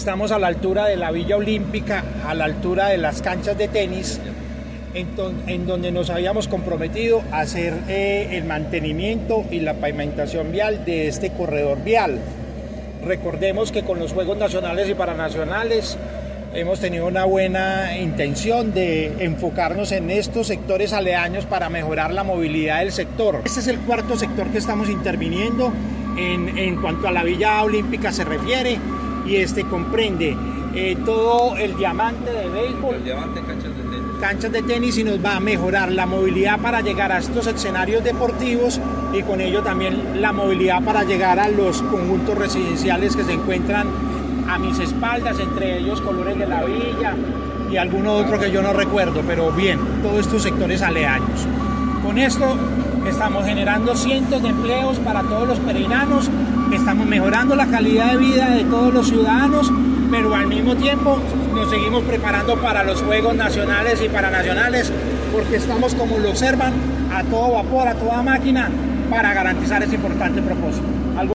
ALCALDE_CARLOS_MAYA.06.mp3